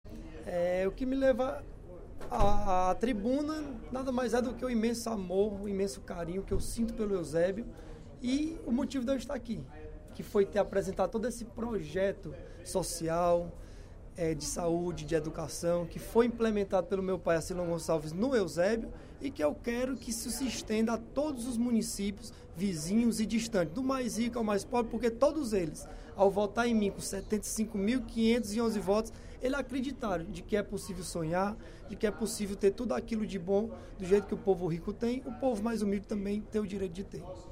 Durante o primeiro expediente da sessão plenária desta quinta-feira (05/02), o deputado Bruno Gonçalves (PEN) informou que foi agraciado, na última sexta-feira, com o título de Cidadão Eusebiense, na Câmara Municipal da cidade.